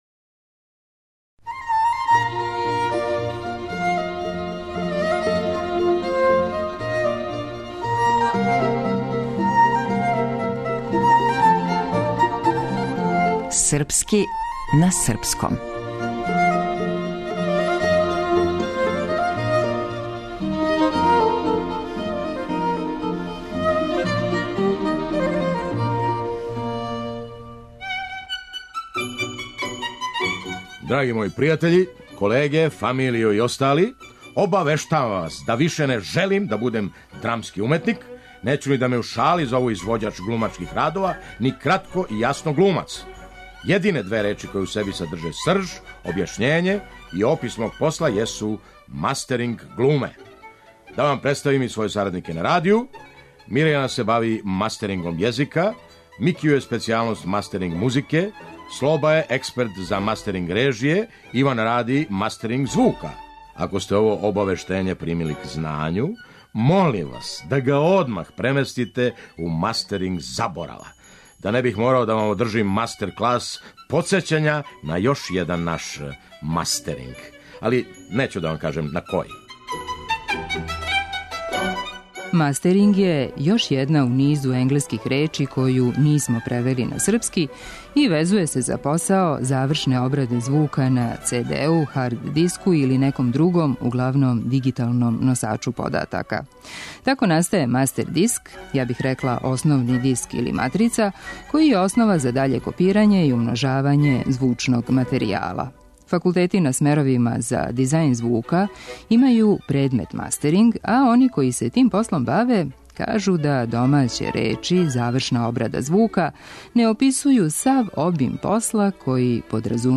Драмски уметник: Феђа Стојановић